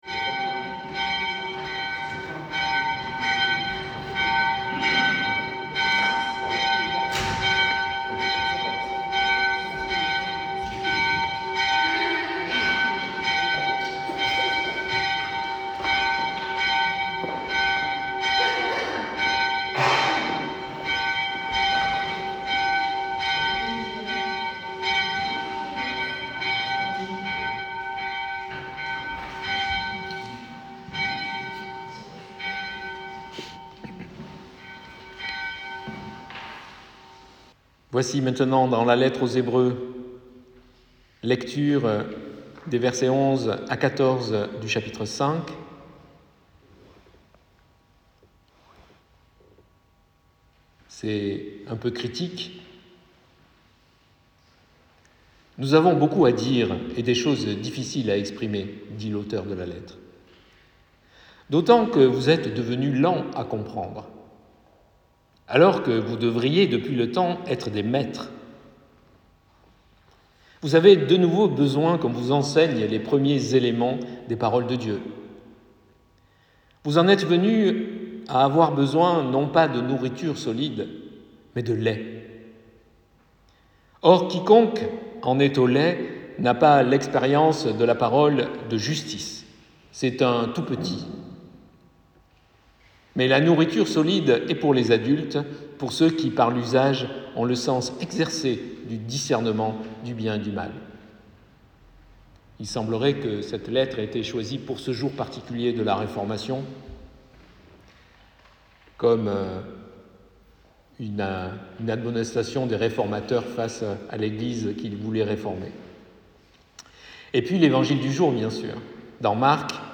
Prédication du 27 octobre 2024 (Réformation).mp3 (30.94 Mo)